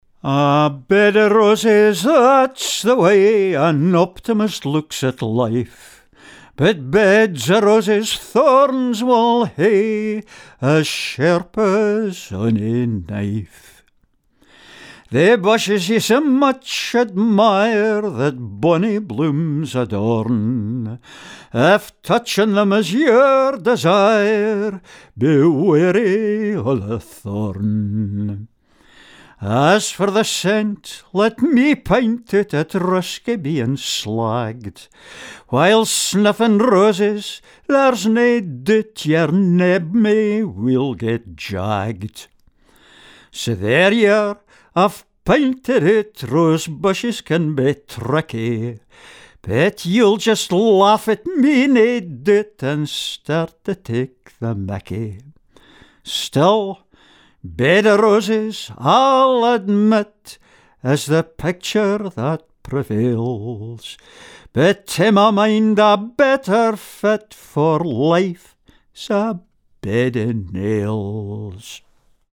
Scots Song